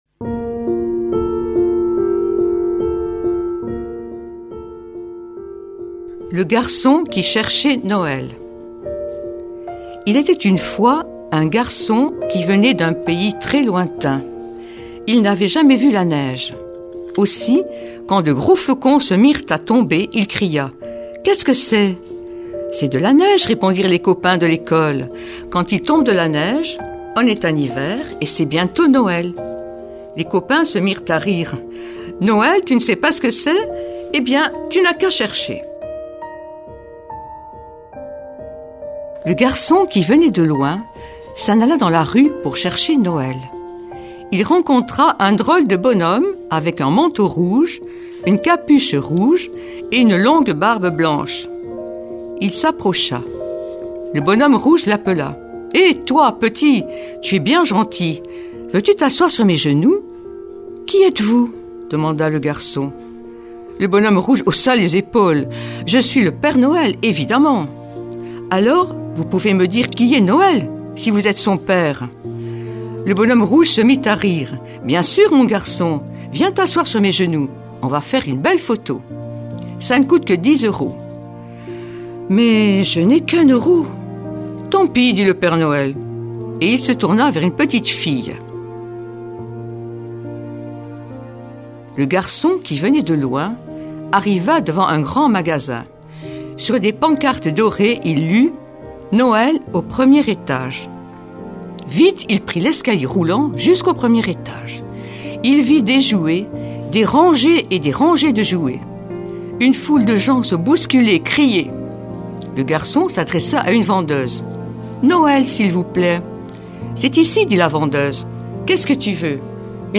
mardi 1er janvier 2019 Contes de Noël Durée 8 min